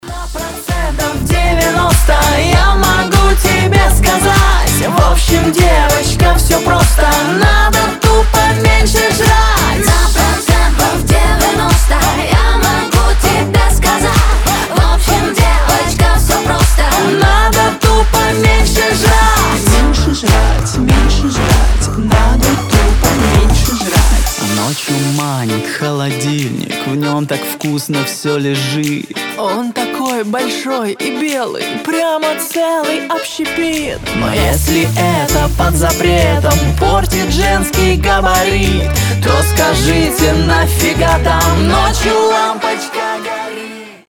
• Качество: 320, Stereo
позитивные
веселые
попса